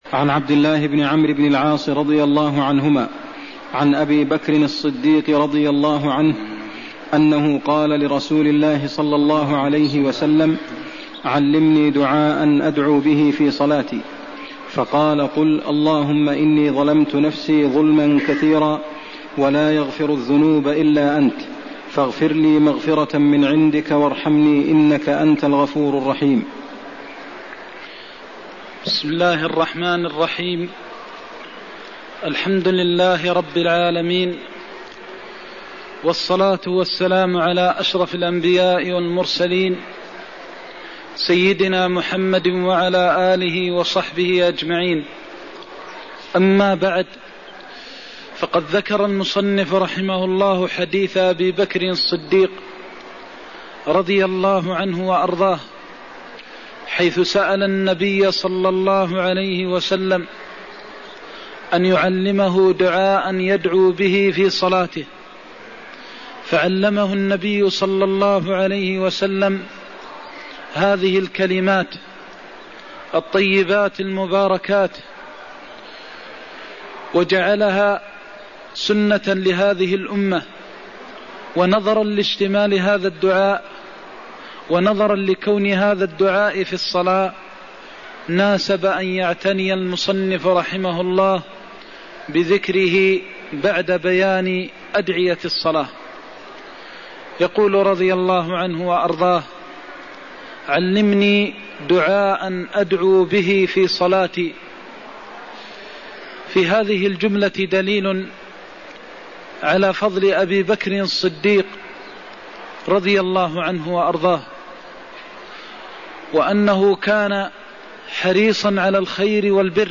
المكان: المسجد النبوي الشيخ: فضيلة الشيخ د. محمد بن محمد المختار فضيلة الشيخ د. محمد بن محمد المختار الدعاء قبل السلام (117)